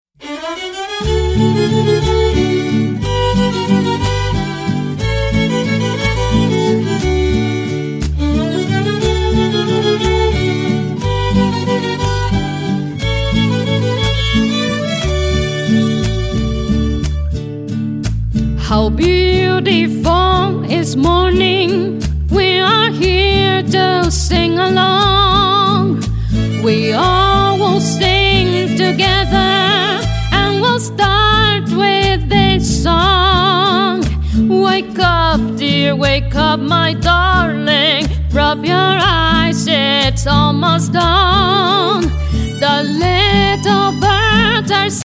. it’s all there in its multi-instrumental glory.